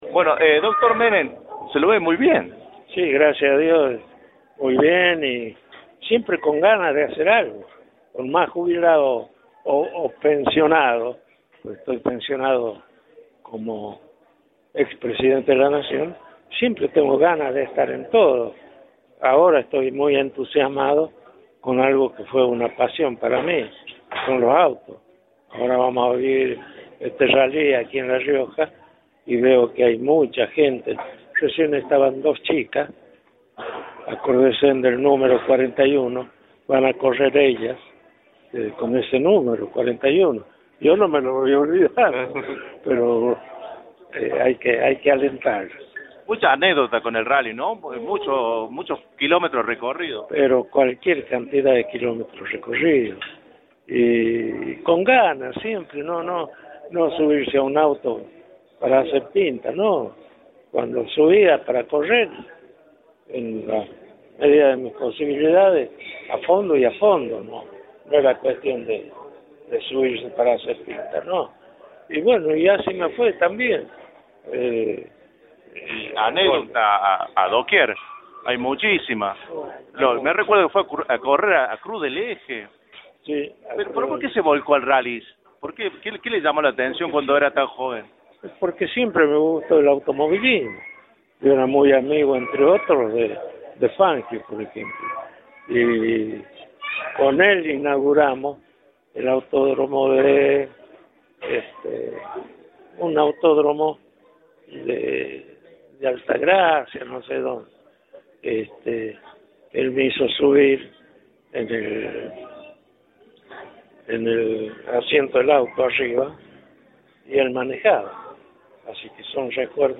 El ex presidente Carlos Menem reapareció en la tarde noche del jueves en una confitería céntrica de la capital riojana, tras que sus amigos le festejaron su cumpleaños 83 en el Golf Club de La Rioja.